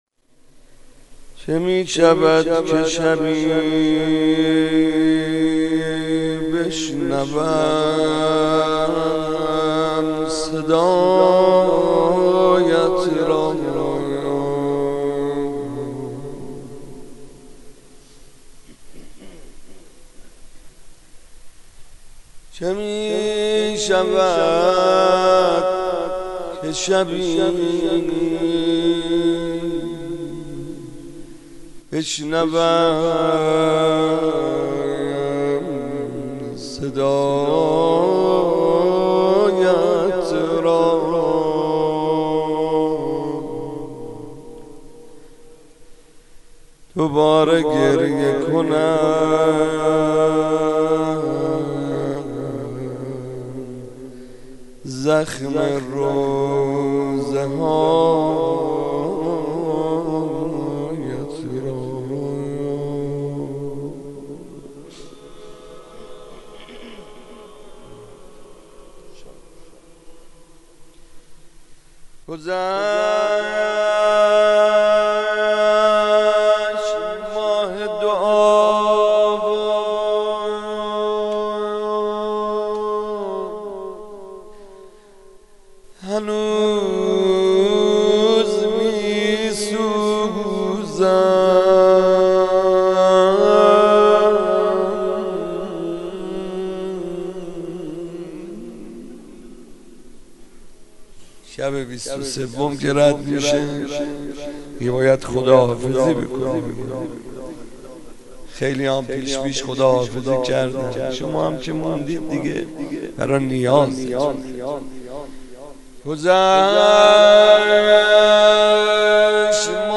مناسبت : شب بیست و چهارم رمضان
قالب : مناجات